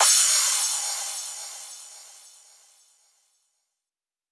Crashes & Cymbals
MUB1 Crash 016.wav